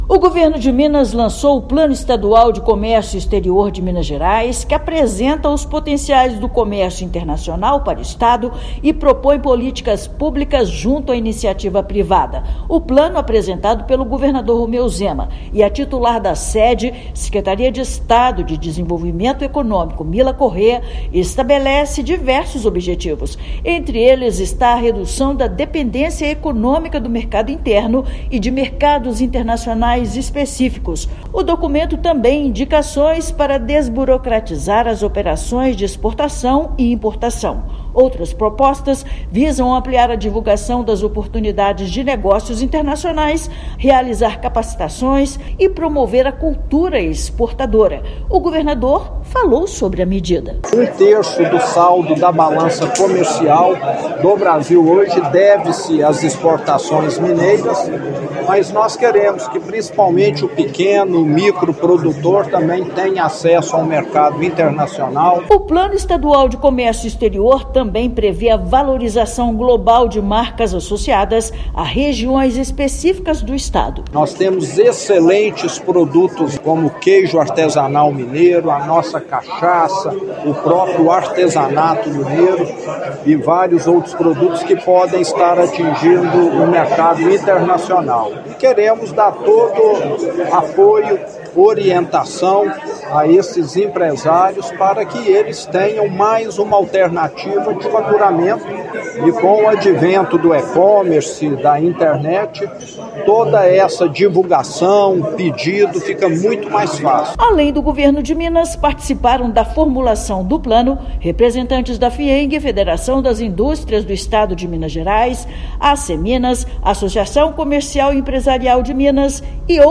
Documento destaca panorama da participação mineira no comércio internacional e propõe iniciativas para abrir novos mercados e ampliar a inserção de empresas locais. Ouça matéria de rádio.